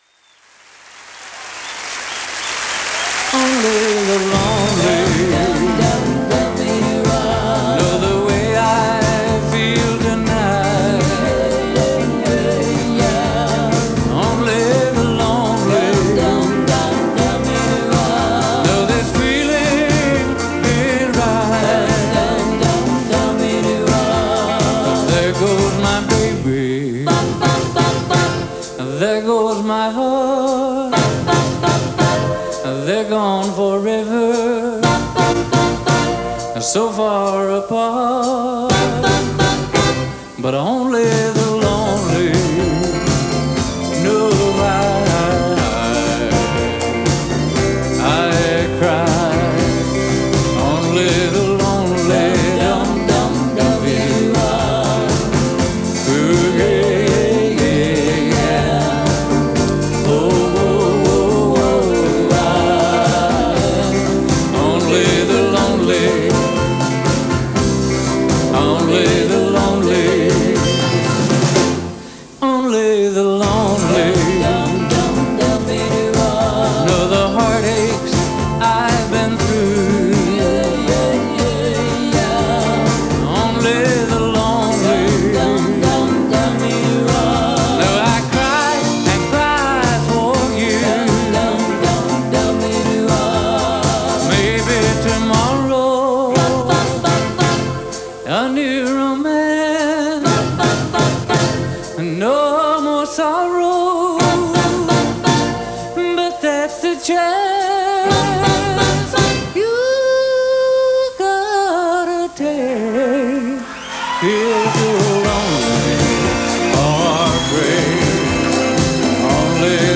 Recorded January 1, 1987 in Houston, Texas
live in Houston
band soundboard
Recorded during his monumental U.S. tour on January 1, 1987.
angelic voice – heart stopping pathos.